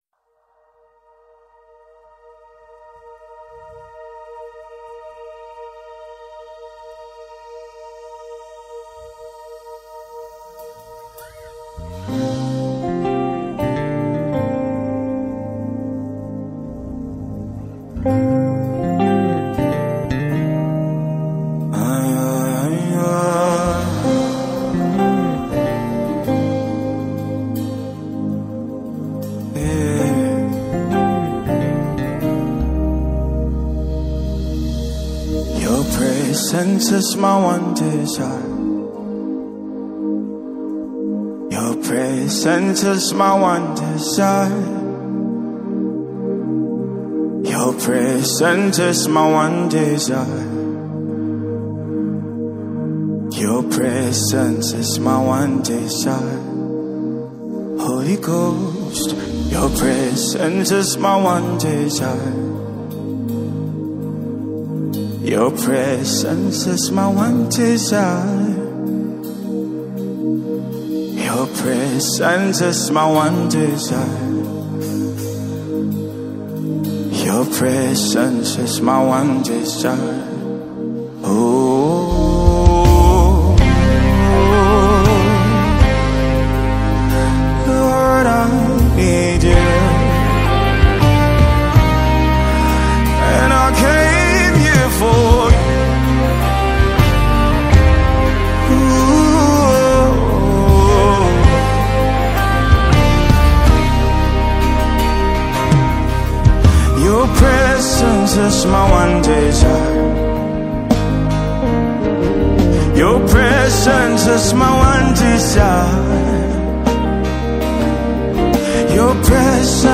September 11, 2024 admin Gospel, Music 0
deep worship single